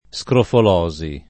vai all'elenco alfabetico delle voci ingrandisci il carattere 100% rimpicciolisci il carattere stampa invia tramite posta elettronica codividi su Facebook scrofolosi [ S krofol 0@ i ] (lett. scrofulosi [ S kroful 0@ i ]) s. f. (med.)